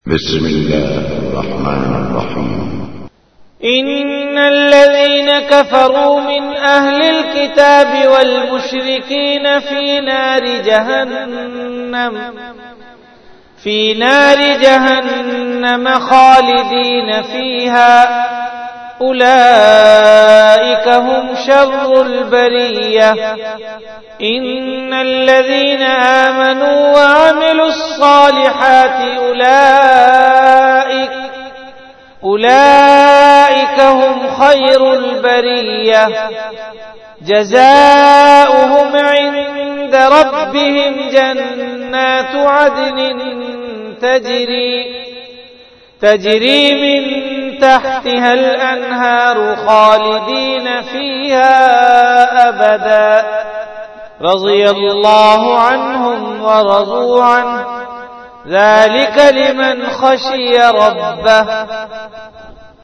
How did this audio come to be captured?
VenueJamia Masjid Bait-ul-Mukkaram, Karachi Event / TimeAfter Isha Prayer